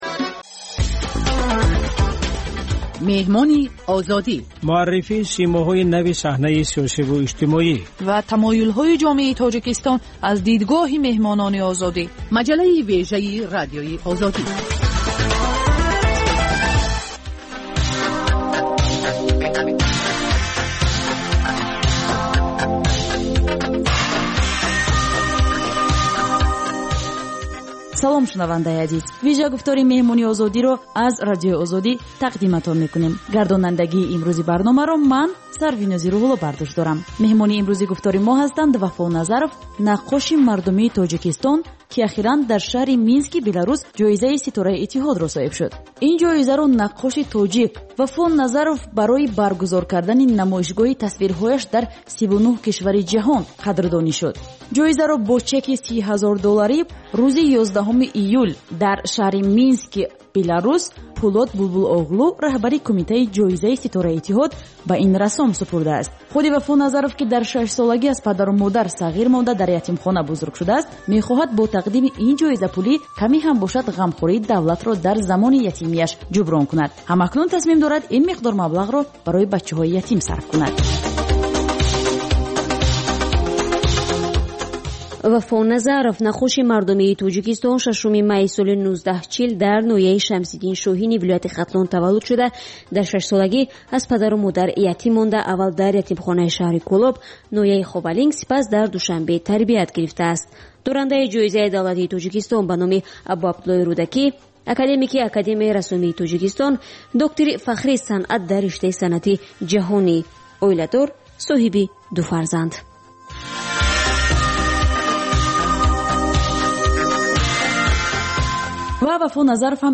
Гуфтугӯи ошкоро бо шахсони саршинос ва мӯътабари Тоҷикистон, сиёсатмадорону ҷомеашиносон, ҳунармандону фарҳангиён